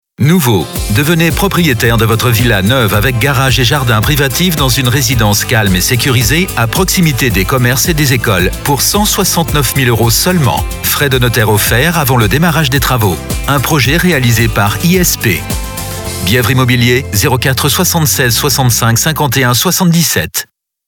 Voix caméléon 3 octaves.
Comédien Français, voix grave médium caméléon.
Sprechprobe: Werbung (Muttersprache):